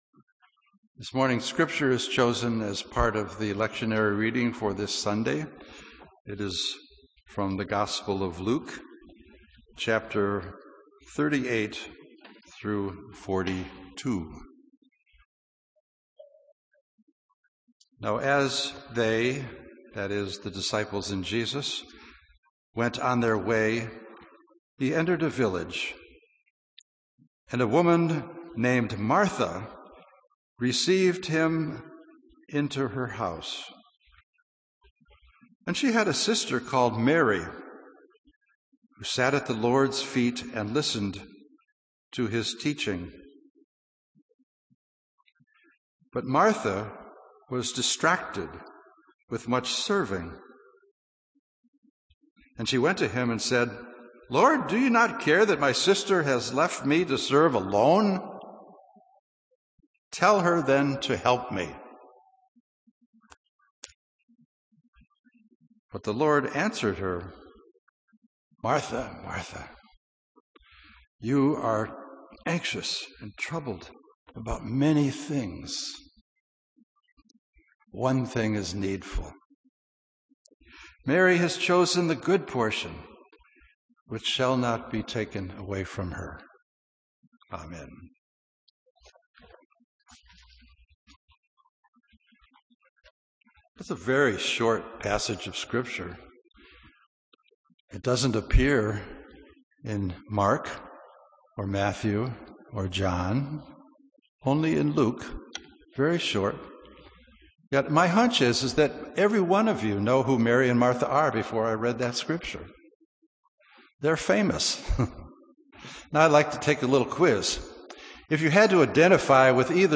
Recorded Sermons - The First Baptist Church In Ithaca